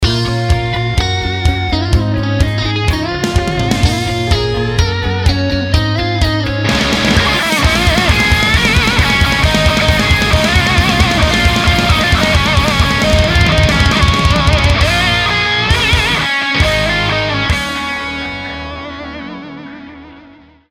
это гитарист такой шикарный